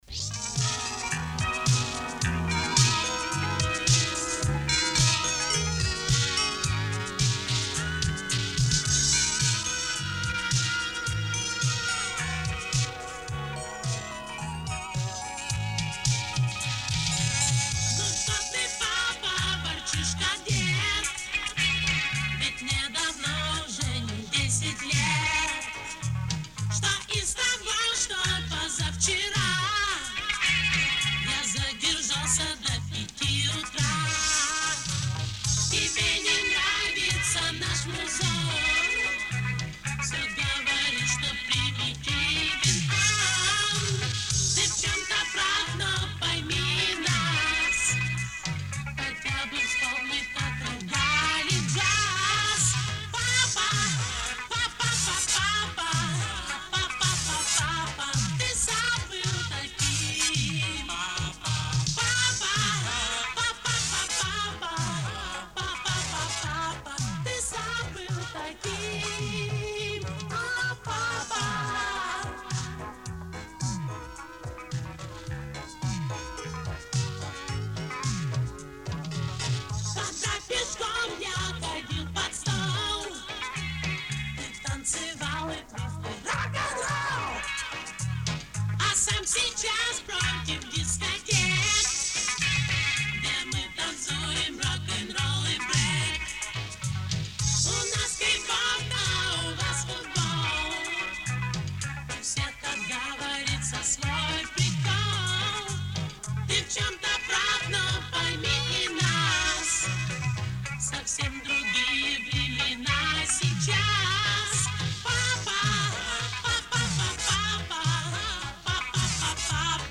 Мною за 20 рублей была куплена  кассета TDK-D-90.